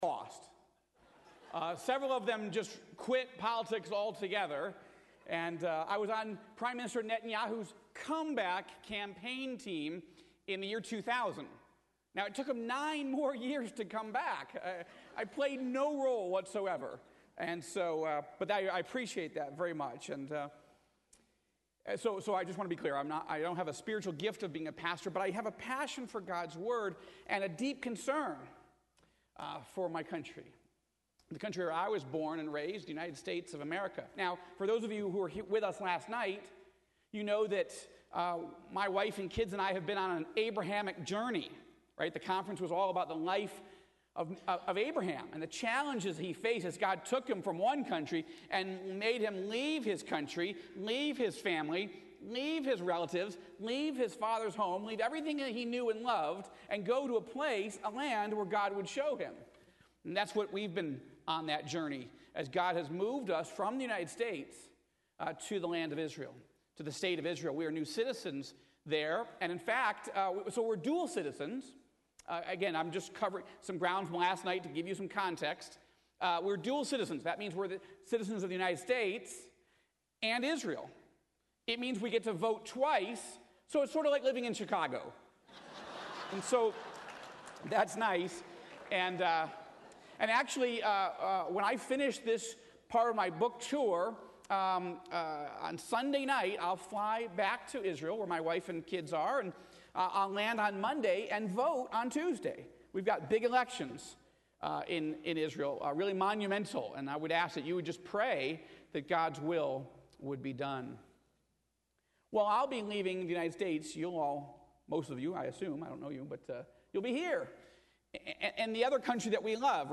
Joel Rosenberg: 2 Chronicles 34:1-28 2 Chronicles 34:1-28 · Mar 11, 2015 · Joel Rosenberg Listen to guest speaker Joel Rosenberg giving a message today on March 11th, 2015 starting in The Book of 2 Corinthians Chapter 34, verse 1.